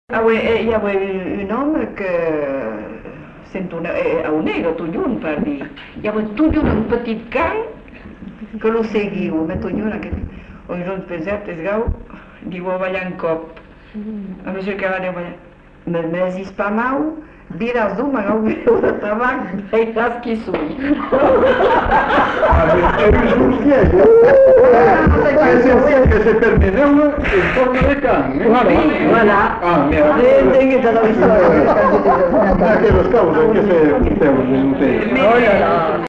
Aire culturelle : Bazadais
Lieu : Uzeste
Genre : conte-légende-récit
Type de voix : voix de femme
Production du son : parlé
Classification : récit de sorcellerie